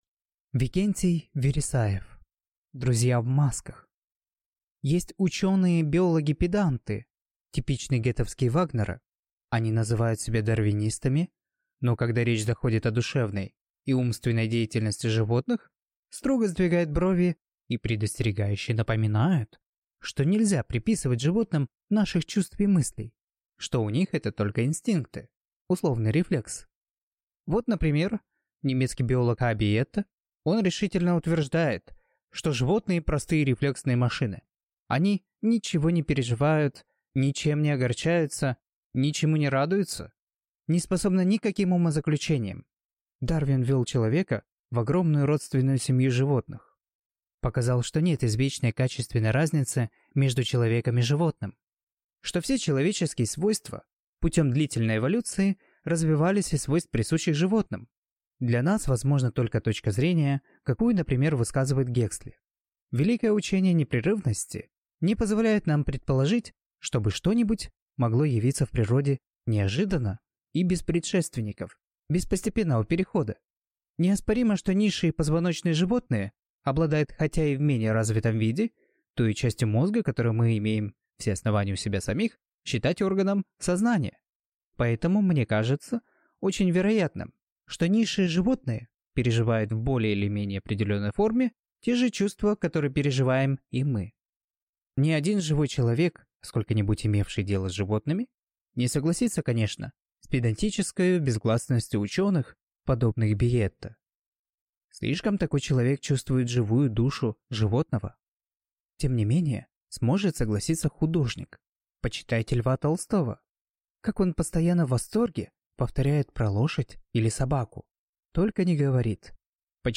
Аудиокнига Друзья в масках | Библиотека аудиокниг